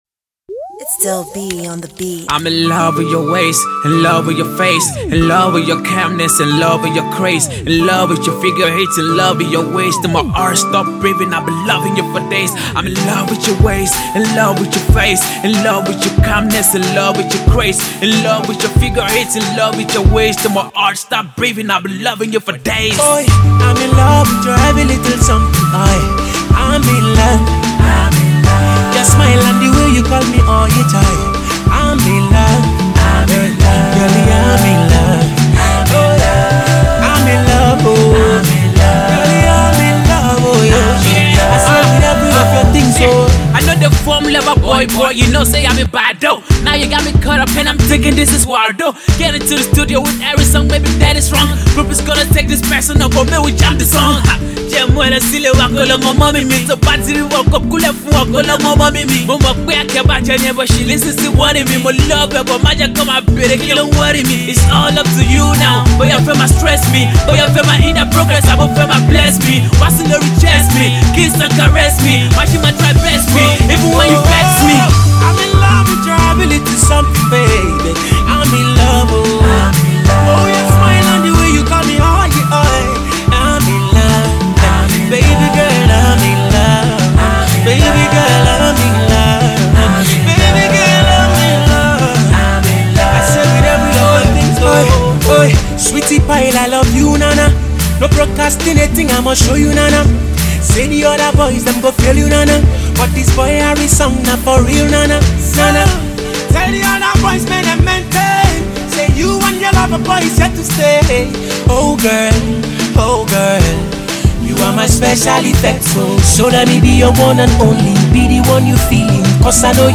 It's another love song for the season.